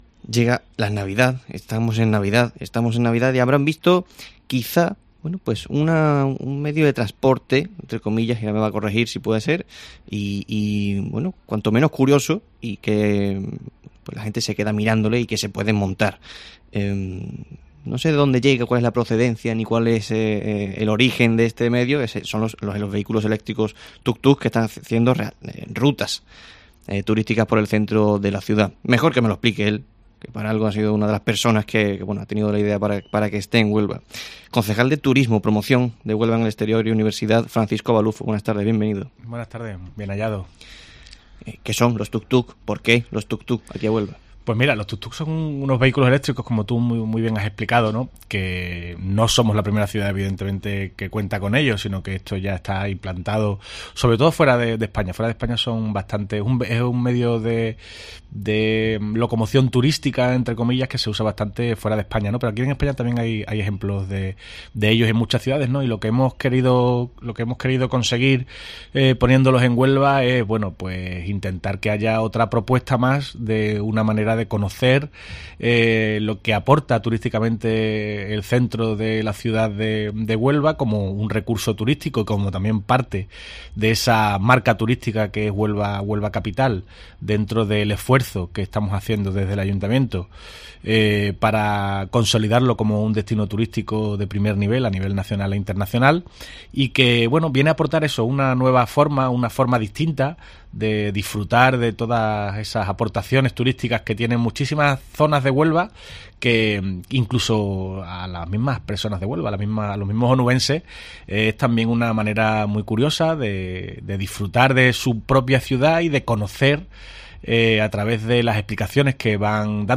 AUDIO: Francisco Baluffo, concejal de Turismo del Ayuntamiento de Huelva, informa sobre los Tuk Tuk y hace balance del puente, de este 2019 y de los...